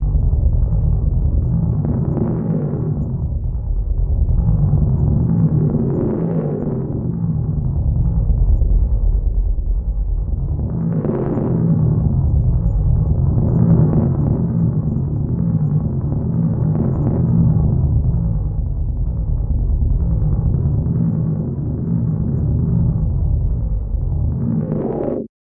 描述：电子振荡器的低音声音在一个永久重复的极低范围的序列中，通过连续变化的滤波器值来区分。声音元素是重叠的。
Tag: 低音 过滤 重复 序列